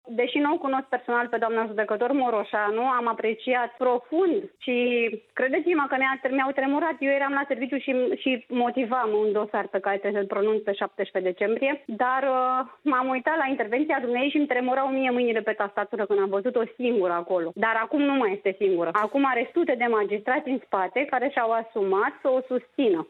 Judecătoarea Sorina Marinaș, de la Secția Penală a Curții de Apel Craiova, a declarat aseară, la Euronews România, că este sigură că urmează „repercusiuni” ale Inspecției Judiciare împotriva magistraților „care au îndrăznit” să vorbească despre problemele din justiție.